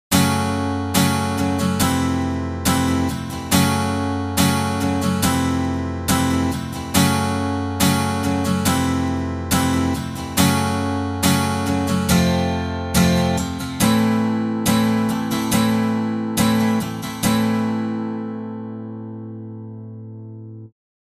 demo-2中的前5个小节是完全一样的，但是第6小节变成了只有一个重扫的特殊小节。
demo-1、demo-2和demo-3都是Akkord Guitar的原始音色，导出时没有加任何均衡或效果器（demo-2和demo-3中只加了一个立体声扩展）。